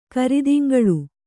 ♪ karidiŋgaḷu